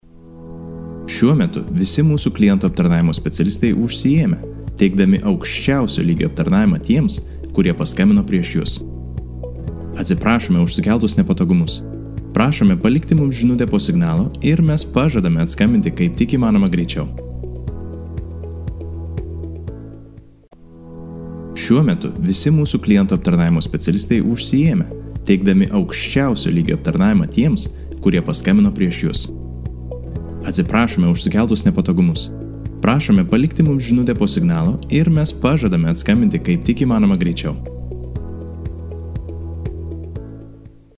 男立陶宛06 立陶宛语男声 MG动画 沉稳|娓娓道来|科技感|积极向上|时尚活力|素人